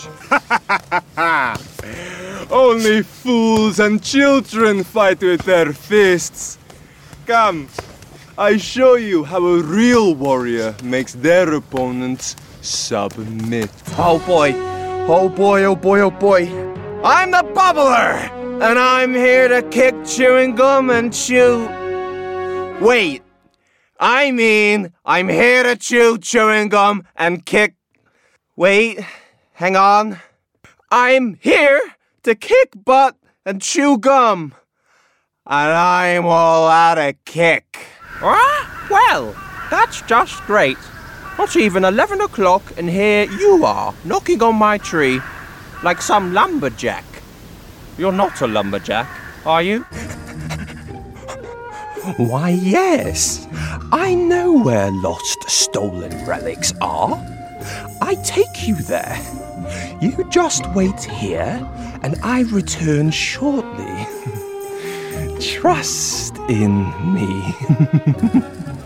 20s – 30s. Male. Manchester.
Animation